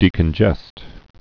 (dēkən-jĕst)